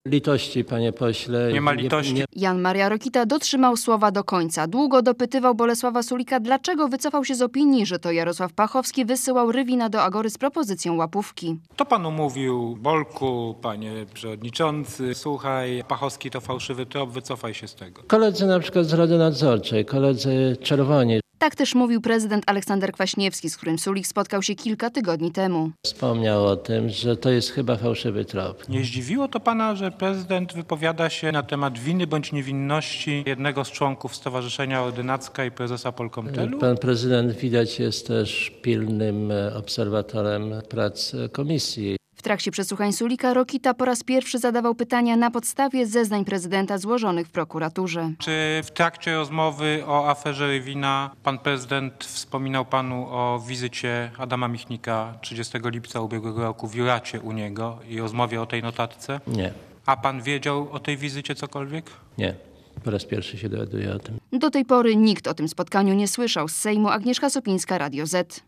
Posłuchaj relacji reporterki Radia Zet (2.2 MB)